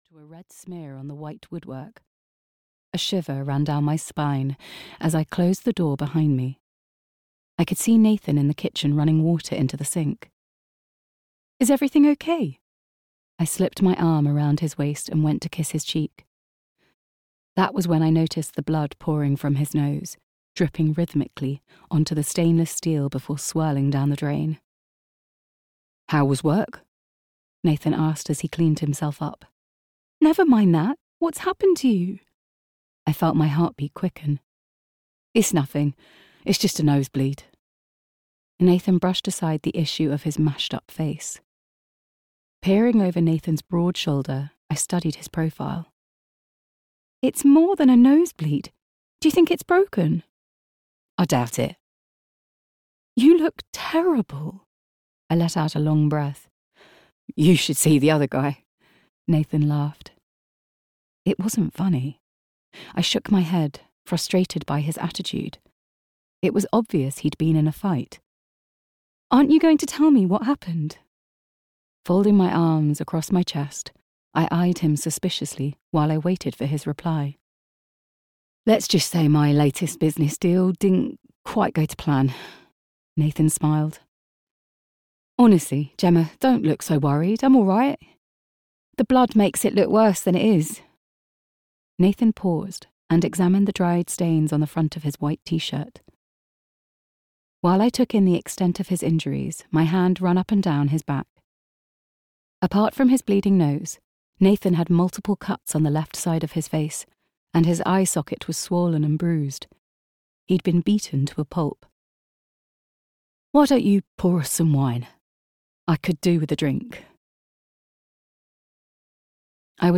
Risking It All (EN) audiokniha
Ukázka z knihy